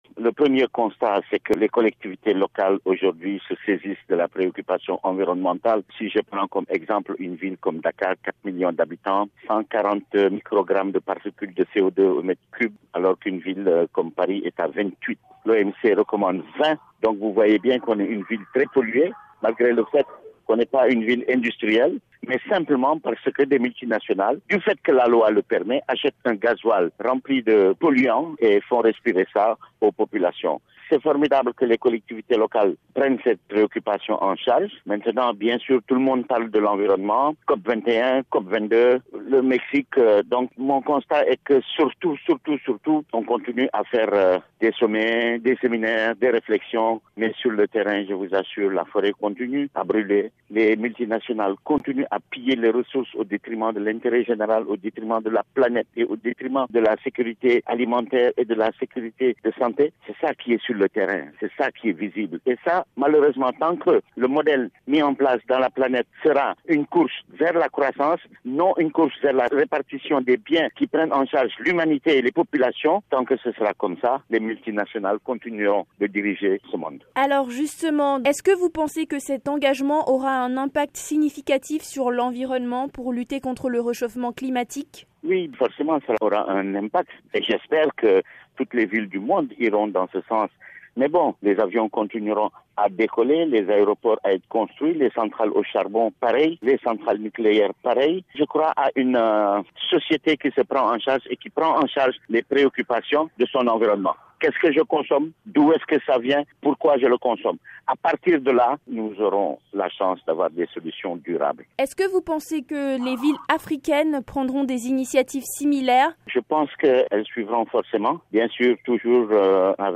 expert en changement climatique joint